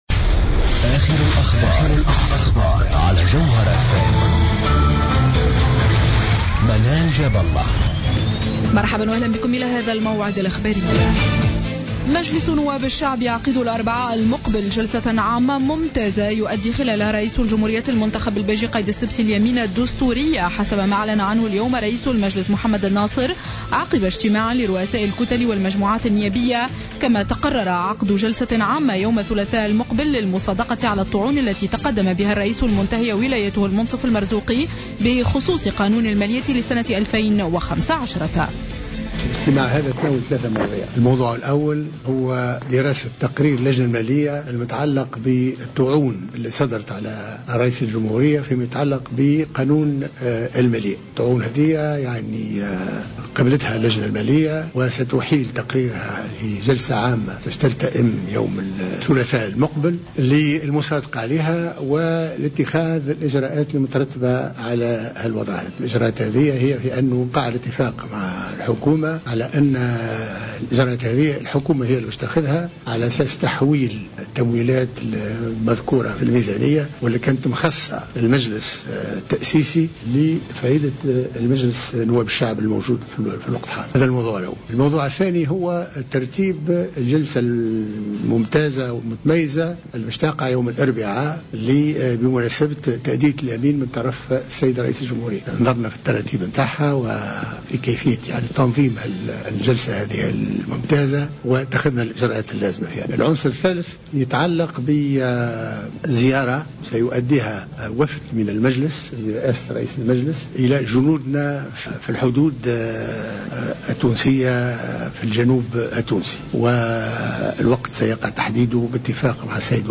نشرة أخبار السابعة مساء ليوم الجمعة 26-12-14